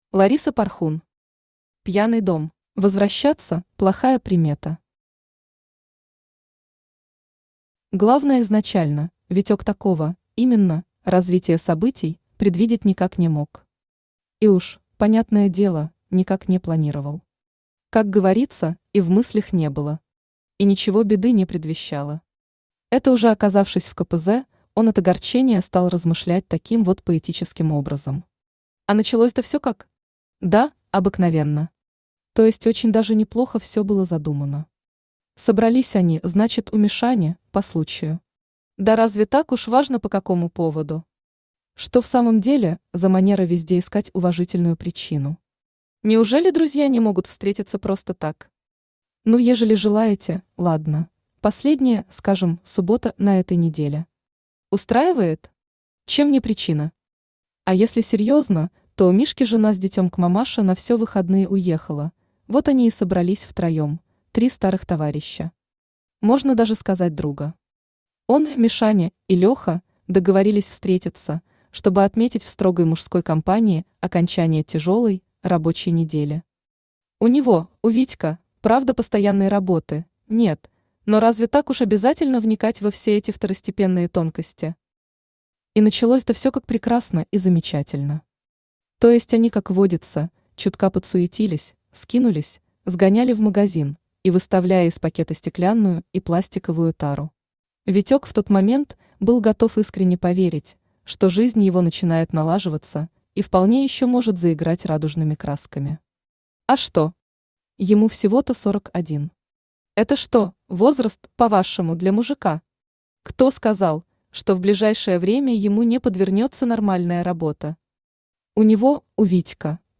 Aудиокнига Пьяный дом Автор Лариса Порхун Читает аудиокнигу Авточтец ЛитРес.